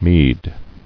[meed]